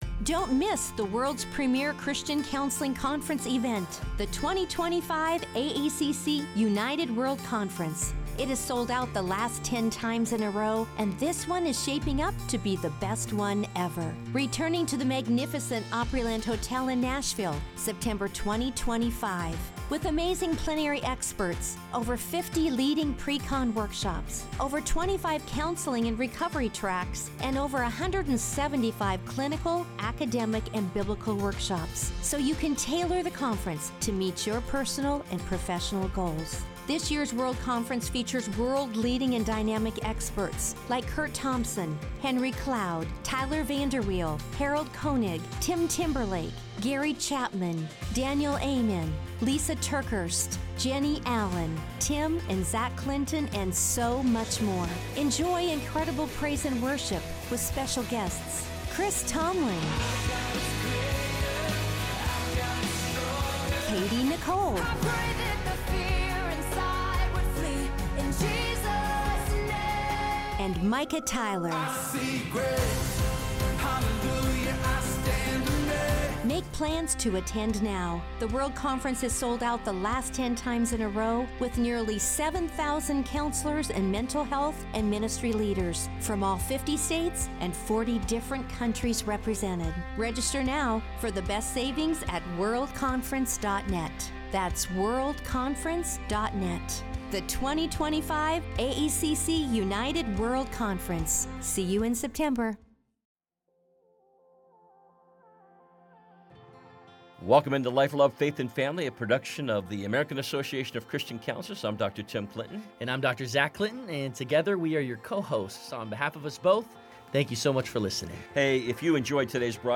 In this inspiring conversation, Dr. Carson reflects on his journey from growing up in poverty to becoming a trailblazer in neurosurgery and a respected leader in public service. Now stepping into his new role as Vice Chair of the Religious Liberty Commission, he shares his vision, concerns, and unwavering commitment to faith and freedom.